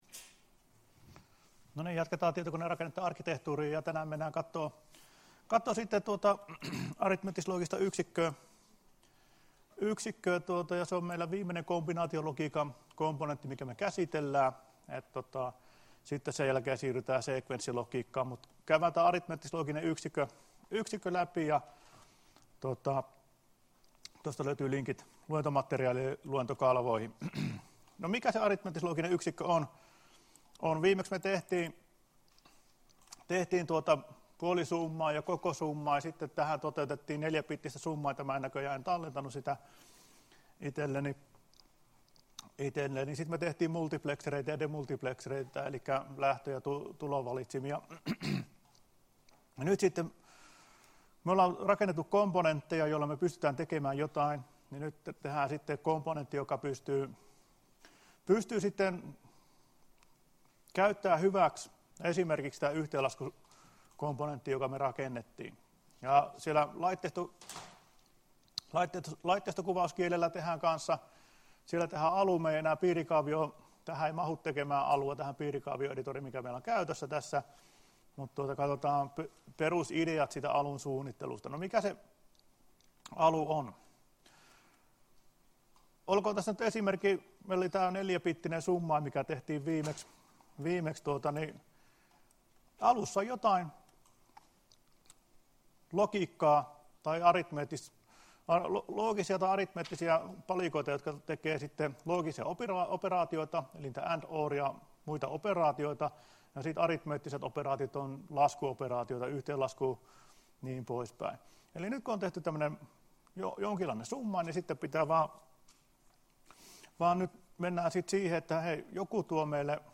Luento 20.11.2017 — Moniviestin